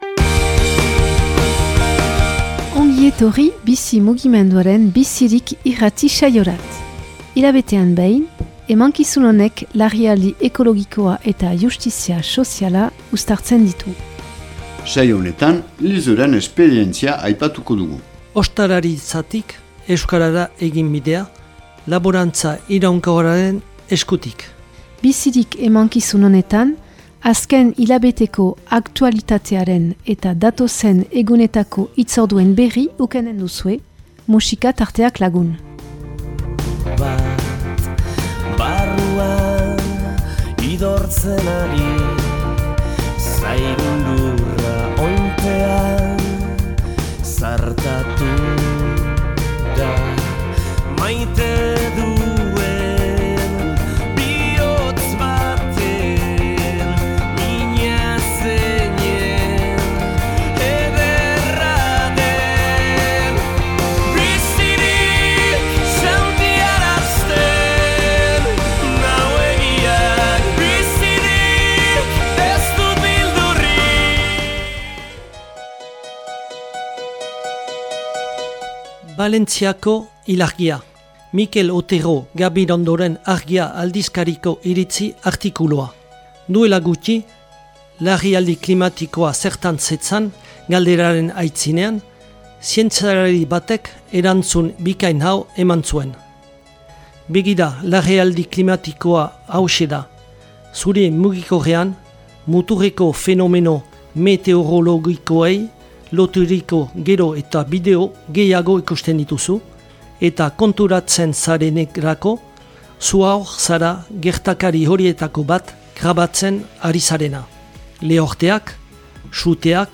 Enregistrement émission de radio en langue basque #121